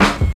44 SNARE 3.wav